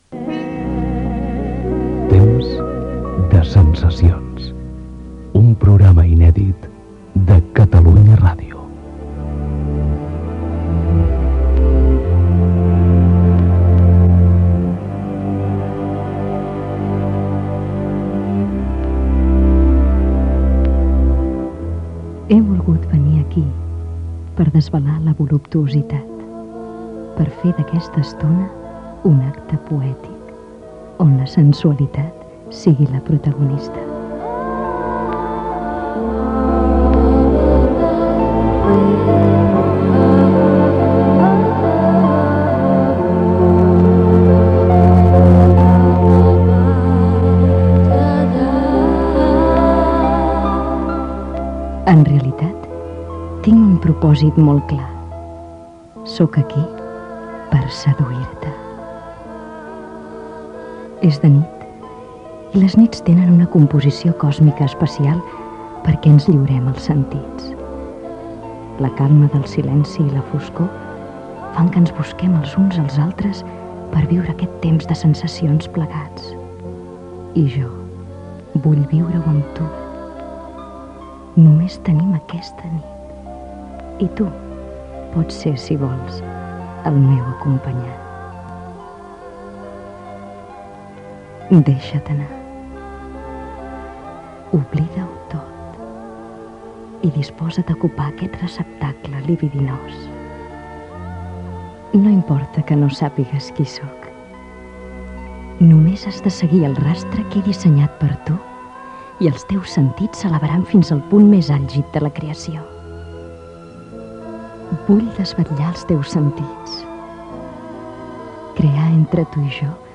Careta del programa i presentació al·ludint a la sensualitat
Entreteniment
FM